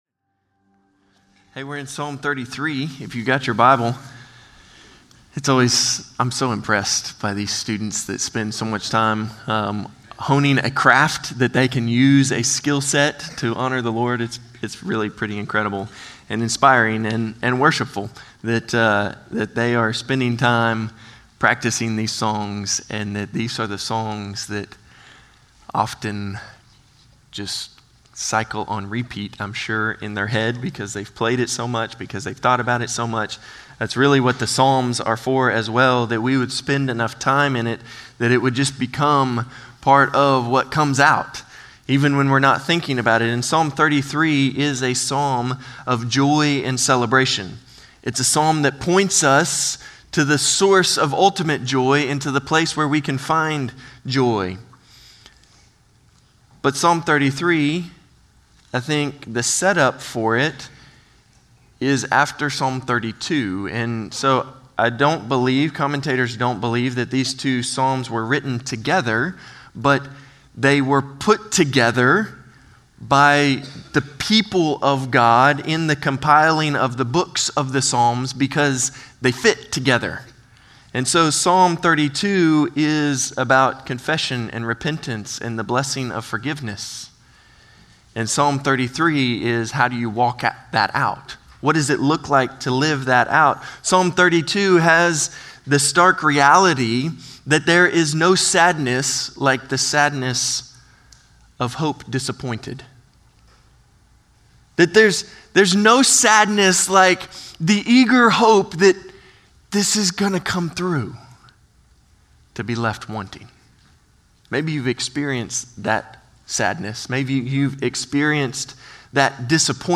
Norris Ferry Sermons Apr. 27, 2025 -- The Book of Psalms -- Psalm 33 Apr 27 2025 | 00:33:41 1x 00:00 / 00:33:41 Subscribe Share Spotify RSS Feed Share Link Embed